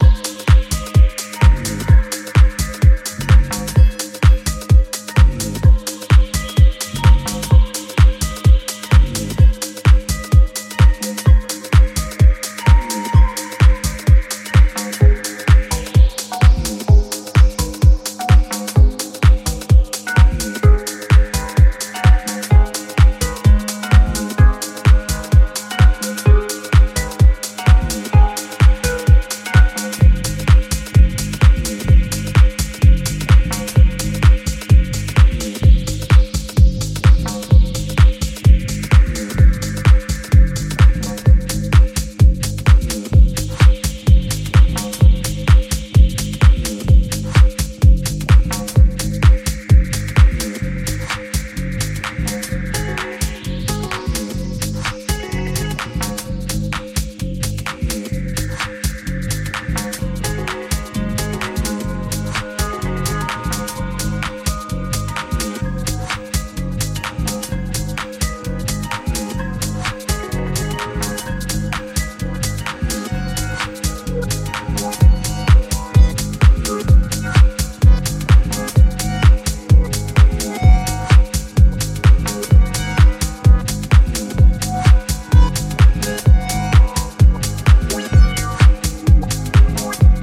物憂げなメロディーと淡々としたグルーヴで深い時間をバッチリはめる